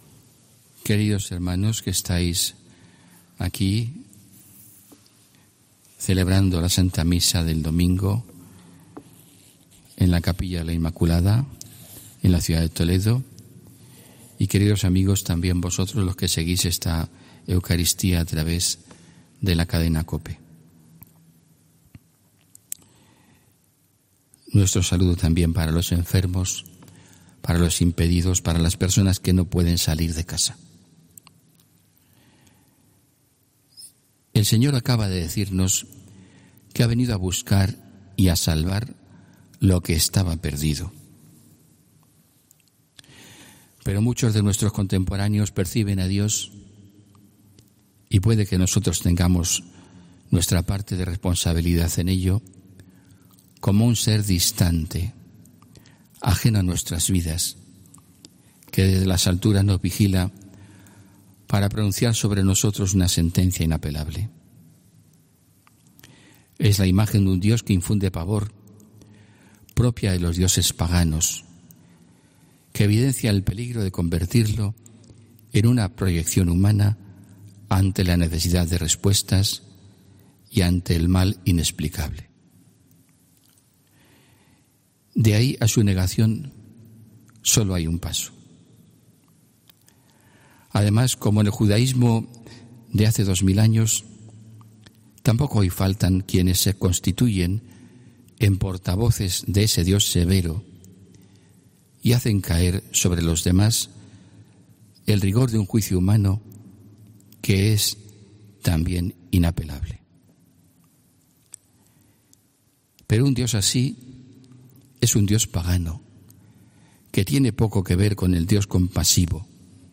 HOMILÍA 3 NOVIEMBRE